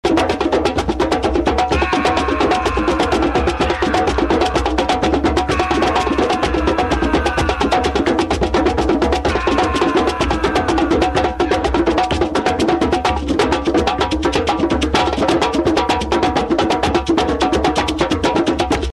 These are 20 second drum circle jam ring tones.